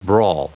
Prononciation du mot brawl en anglais (fichier audio)
Prononciation du mot : brawl